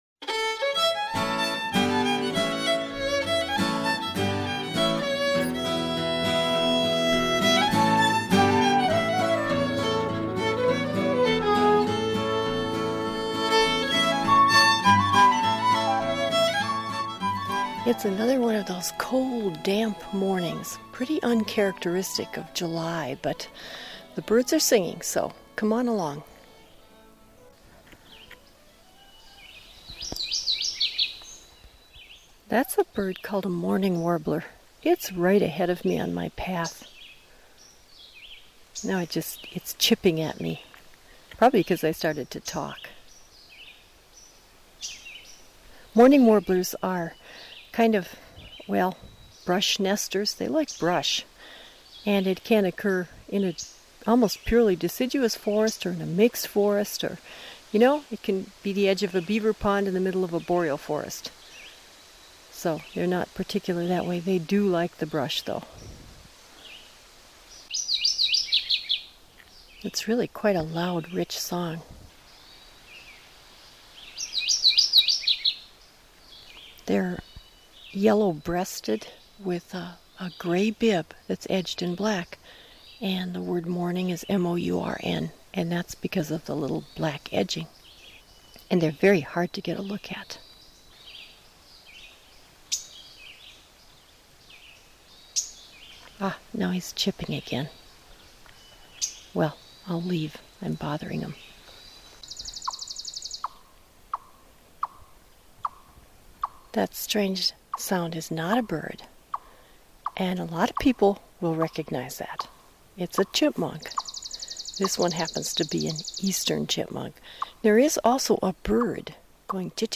Recorded in the fields and woodlands of Northeastern Minnesota